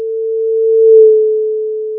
In my setup, the left ear is outputted to the left channel alone and the right ear to the right channel alone; this maximizes the binaural effect.
The proper binaural Doppler effect was most noticeable when the signal was closer to the head and moving more slowly than in the previous examples.
The frequency being emitted here is 440 Hz.